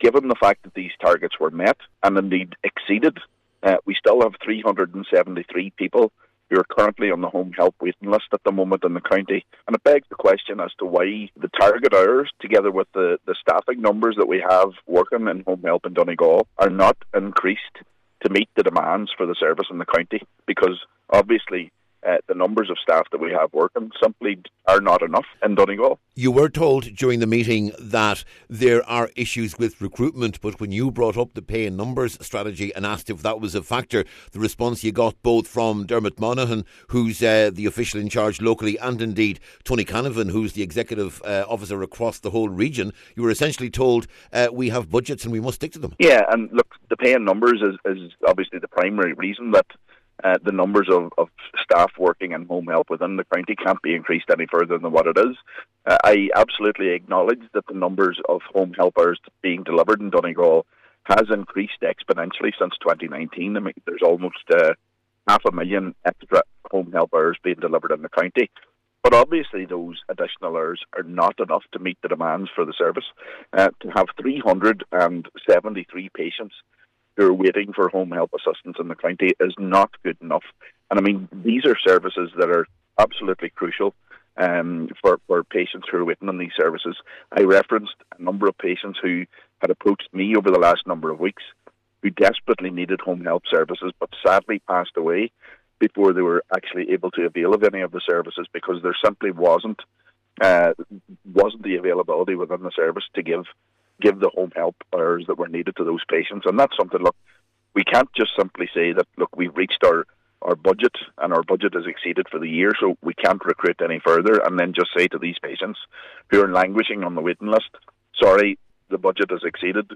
Cllr Doherty says there’s a real need in Donegal, and the failure to meet that need has consequences…………